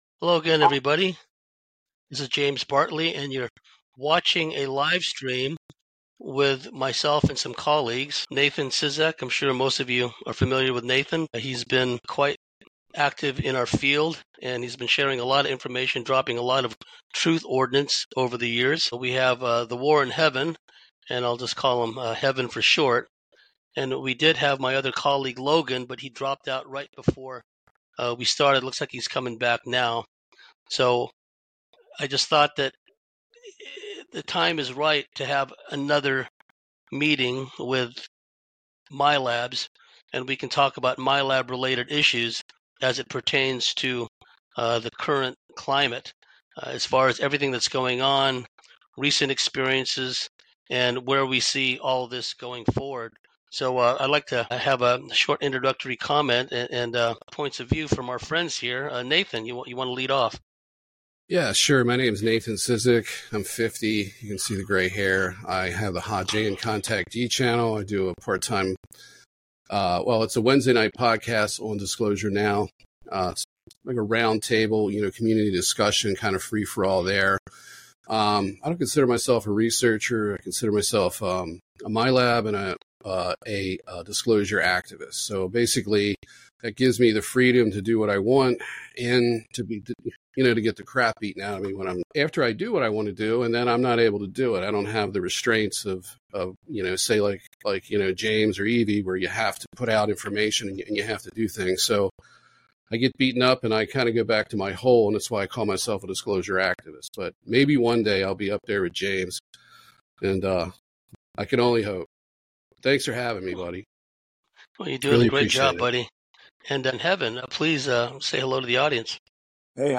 Alternative Realities & Abduction Live Stream Panel - The Cosmic Switchboard
Livestream-panel-discussion-thecosmicswitchboard.com_.mp3&